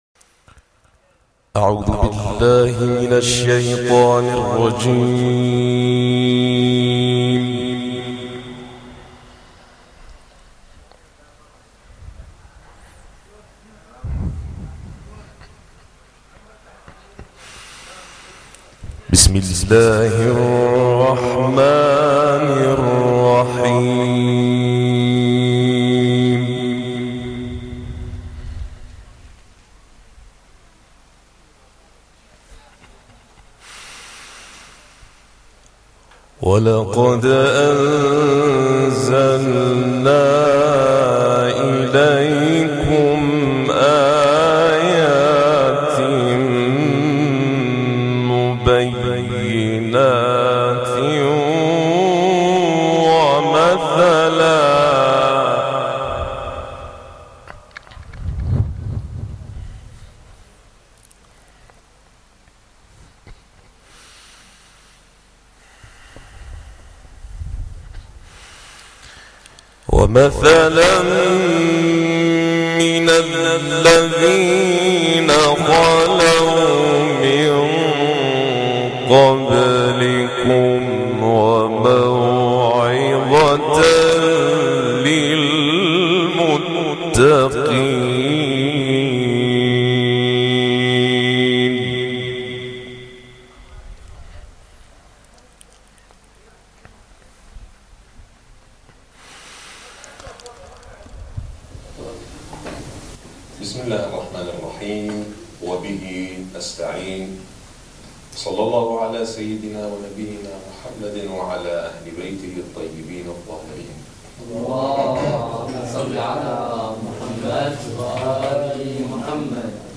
الدرس العاشر - لحفظ الملف في مجلد خاص اضغط بالزر الأيمن هنا ثم اختر (حفظ الهدف باسم - Save Target As) واختر المكان المناسب